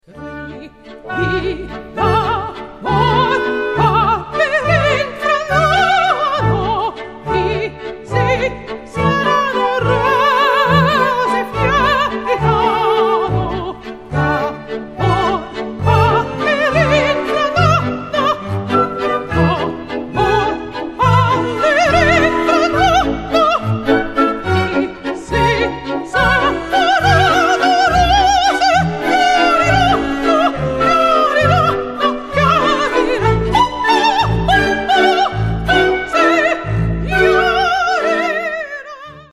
The CD contains both vocal and orchestral pieces.
* World première modern recordings